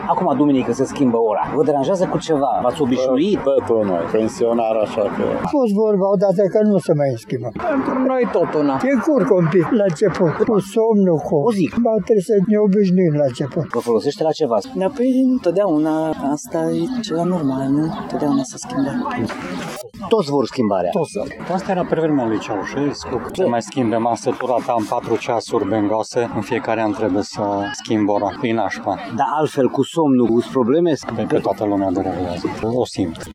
Mulți târgmureșeni susțin că nu sunt foarte afectați, decât eventual la programul de somn, în prima zi după schimbarea orei, iar unii sunt deranjați doar de faptul că trebuie să schimbe ora la ceasurile din casă: